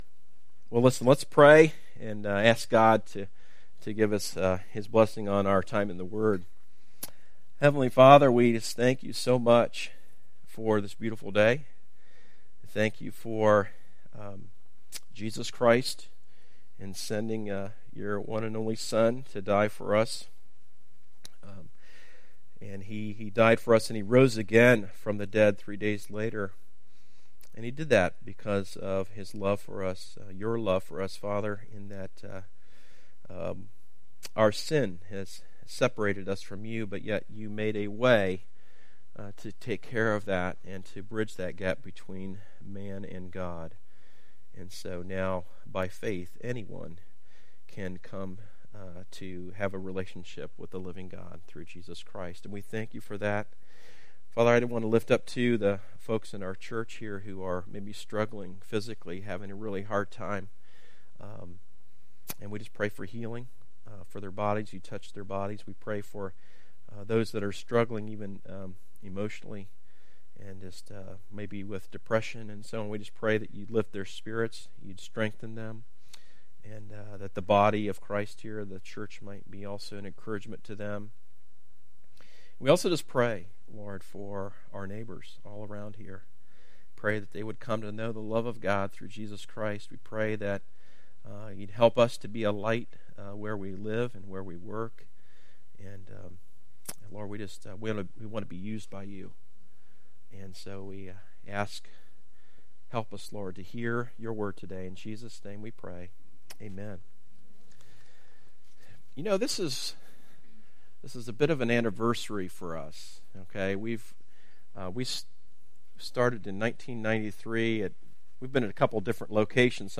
Recent Sermon - Darby Creek Church - Galloway, OH